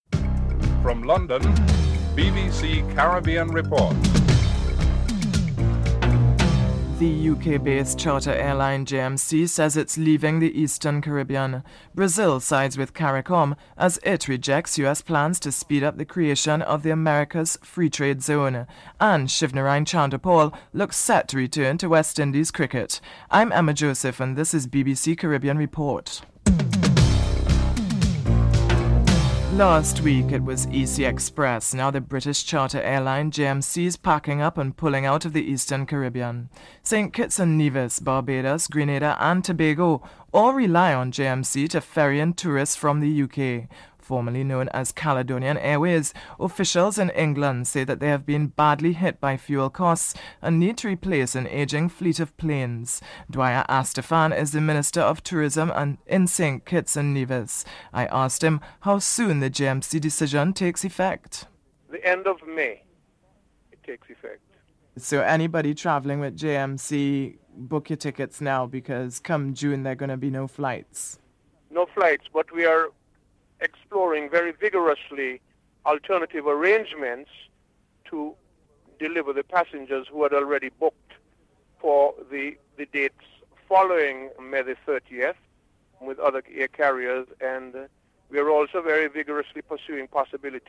1. Headlines (00:00-00:28)
2. United Kingdon-based charter airline JMC says it is leaving the Eastern Caribbean. Minister of Tourism Dwyer Astaphan is interviewed (00:29-03:32)
3. French Prime Minister Lionel Jospin has accused United States President George W. Bush of being inattentive to other realities, other civilizations unnotably to Europe. Member of the European Parliament Glenys Kinnock is interviewed (03:33-06:35)